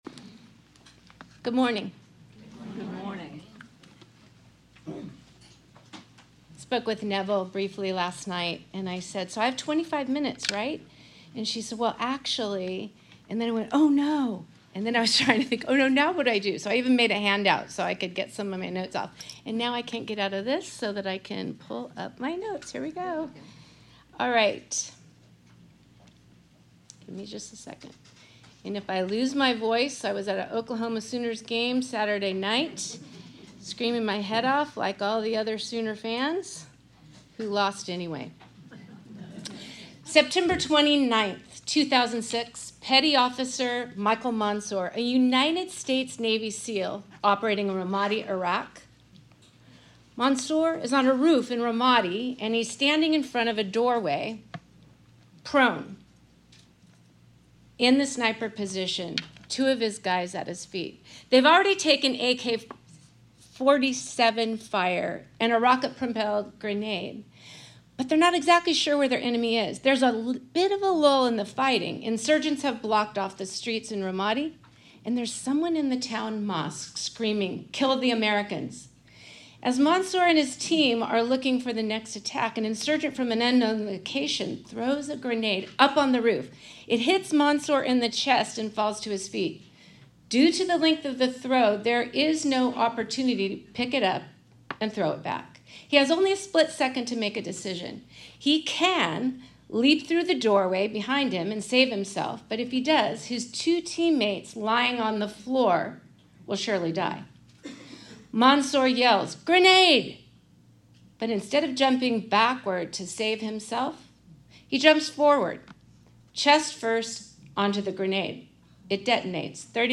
Women of the Word Wednesday Teaching Lesson 2: Isaiah Sep 25 2024 | 00:27:47 1x 00:00 / 00:27:47 Subscribe Share RSS Feed Share Link Embed